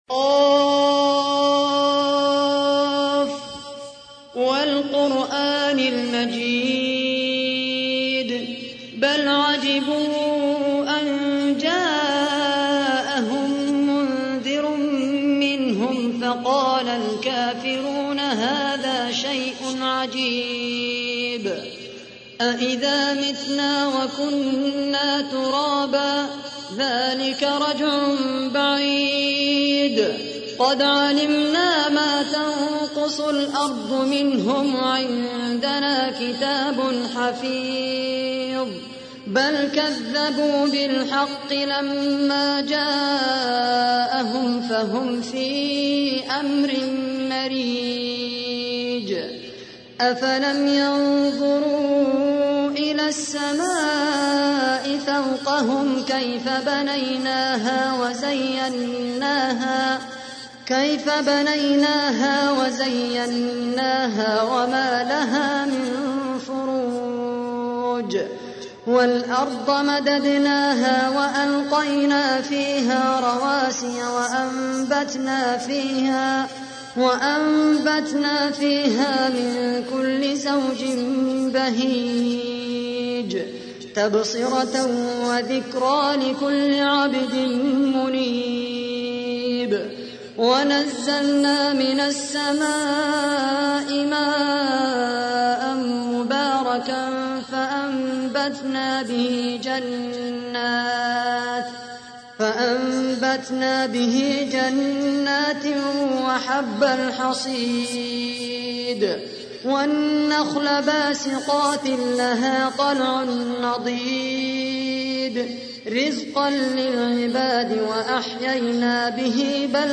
تحميل : 50. سورة ق / القارئ خالد القحطاني / القرآن الكريم / موقع يا حسين